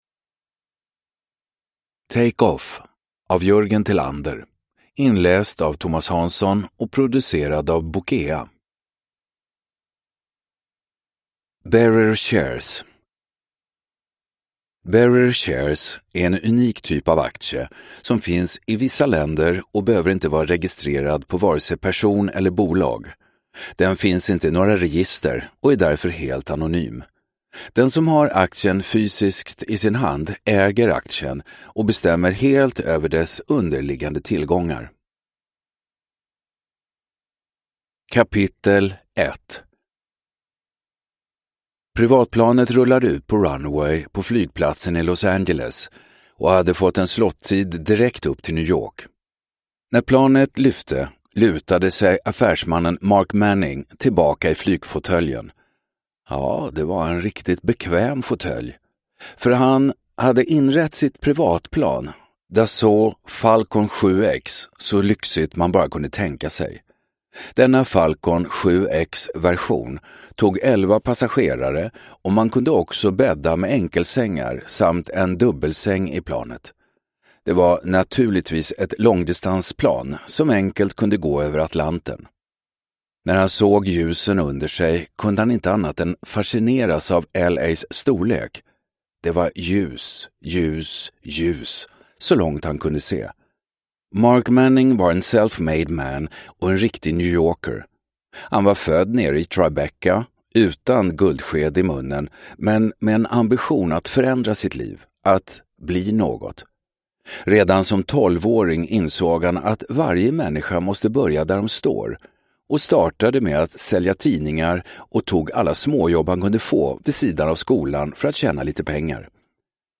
Take off (ljudbok) av Jörgen Tilander